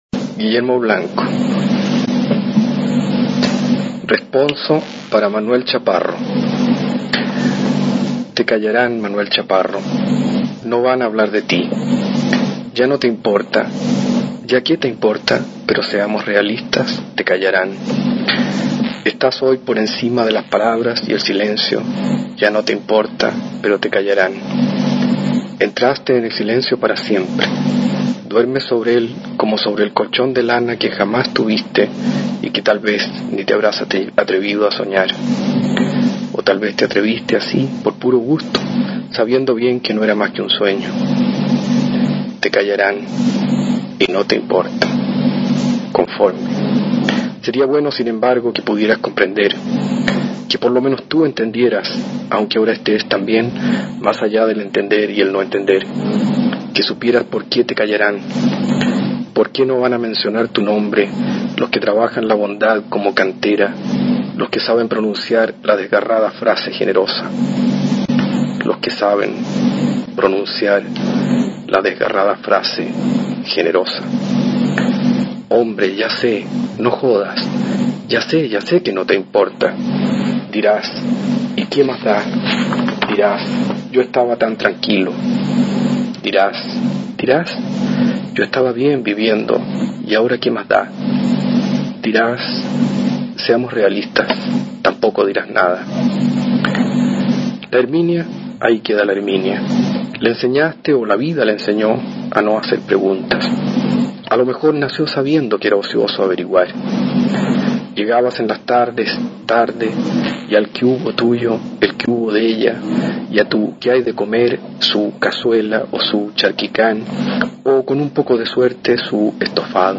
Aquí podrás oír al escritor chileno Guillermo Blanco leyendo su relato Responso para Manuel Chaparro.